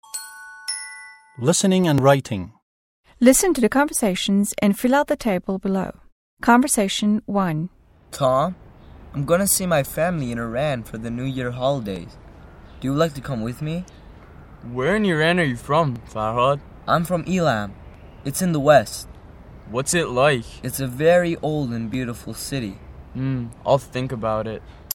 مکالمه ی اول - متن لیسنینگ listening هشتم